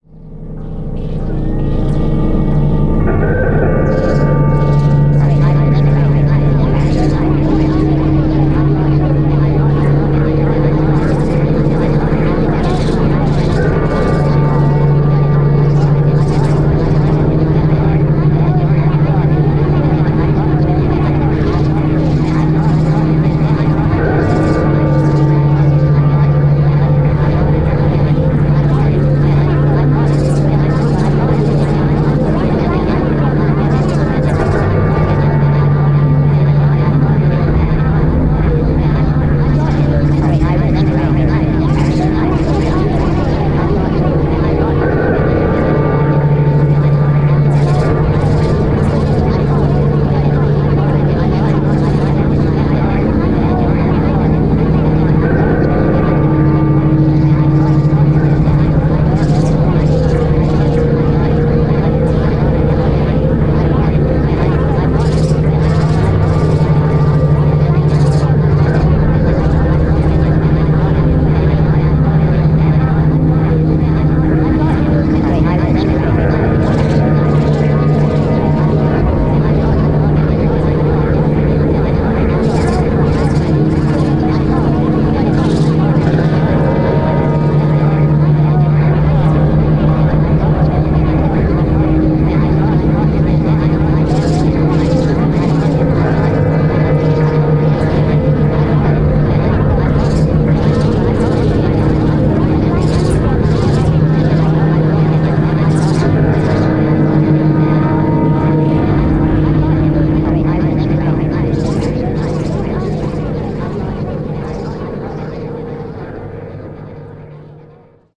Tag: 兔女孩 搅动噪声